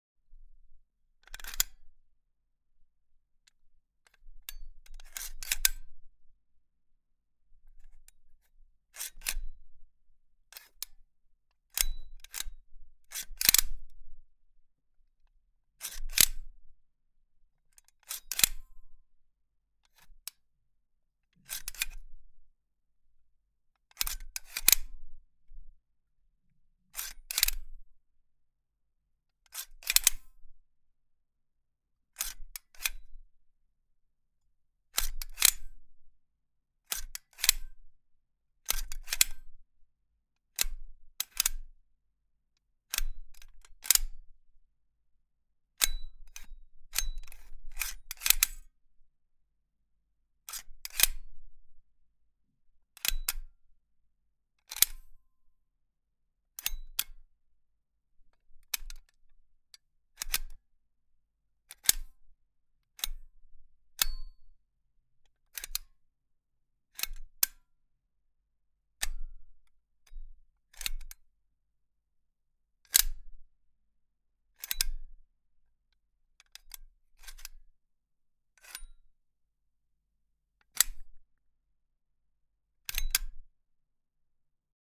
Weapon manipulation. Part1_32_96(compx2,limtr,Eq,transshpr,dnsr)
arms army caliber cinematic effect fight film fx sound effect free sound royalty free Movies & TV